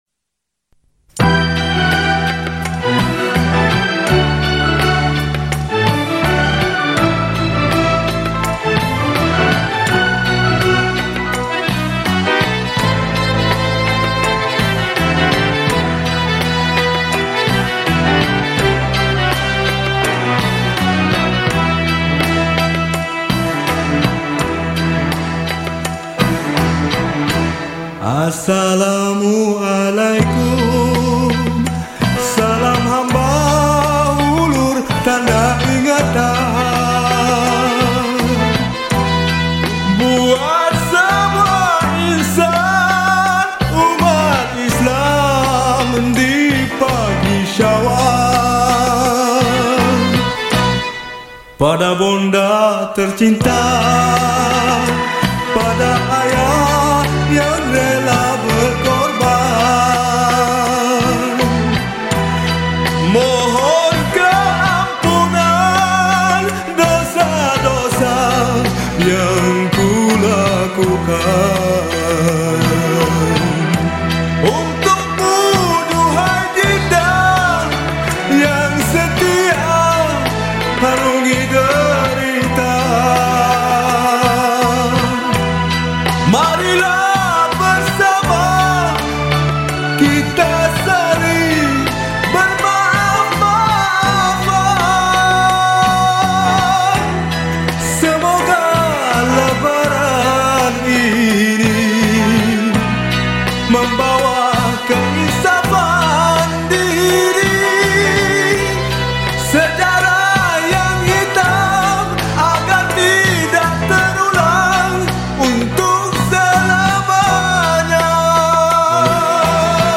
Genre: Raya.